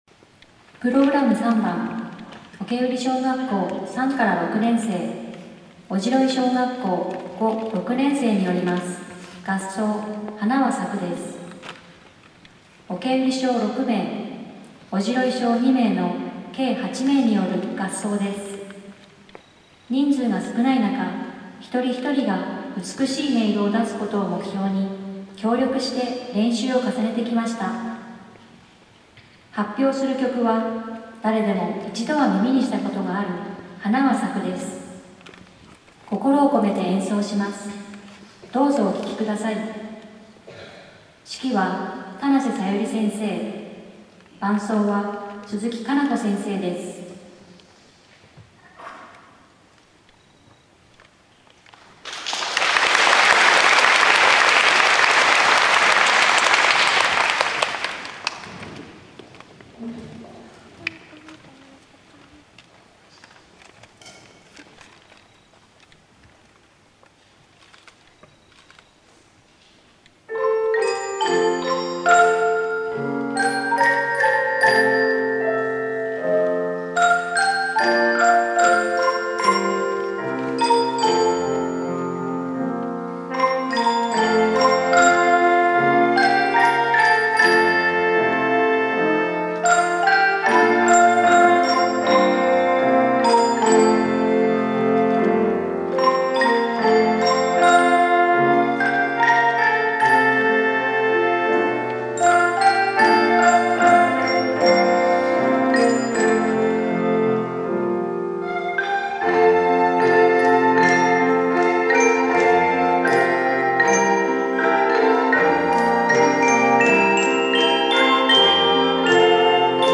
音楽 方部小学校連合音楽祭（於アリオス）
四倉・久之浜、小川・川前方部の小学校連合音楽祭がアリオスで開催されました。
美しい音の重なりに会場からは大きな拍手をいただきました。